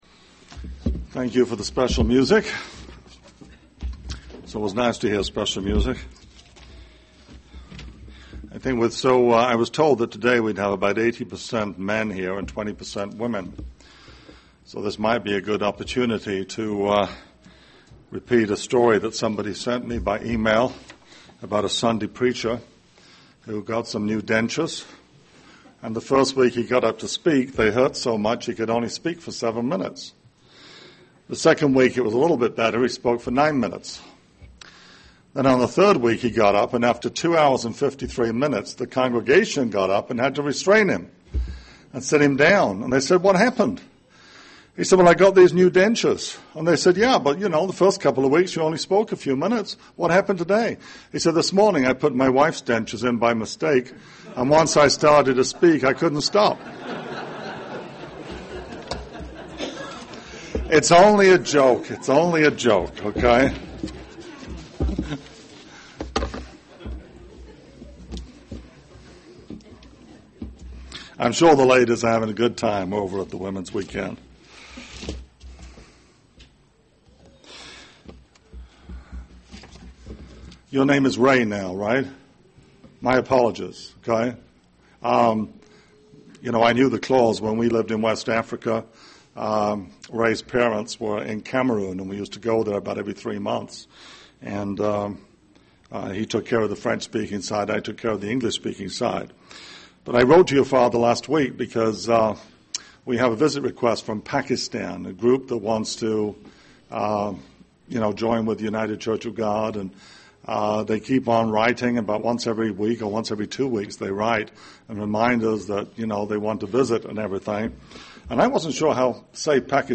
Given in Cincinnati East, OH
UCG Sermon